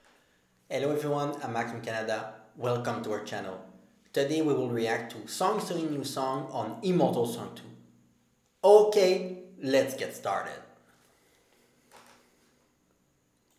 First file is with the fan, low sound, low gain.
Second file is without the fan, also low sound and low gain.
The fan doesn’t bother me that much, and we can still hear the crickets because obv it’s 11h30 PM.
Both files are without any post-production, I might try to add a low remove background noise on Filmora to see if my voice become robotic or not.